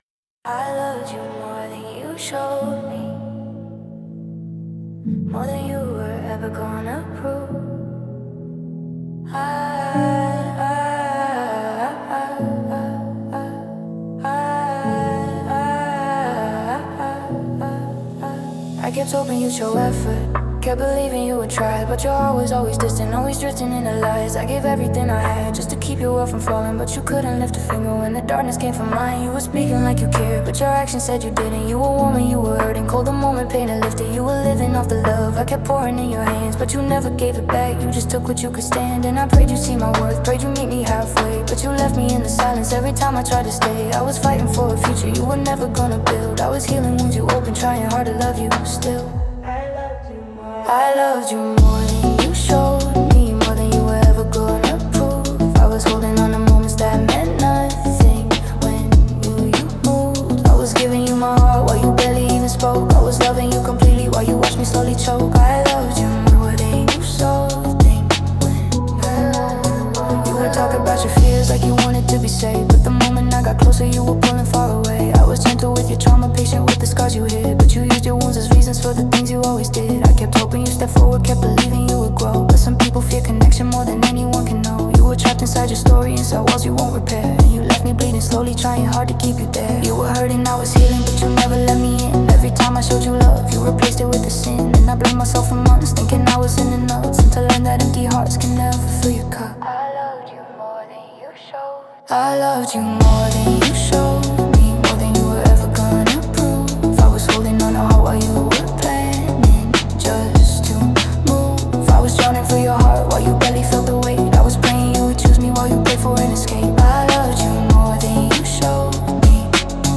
Genre: Phonk Mood: tired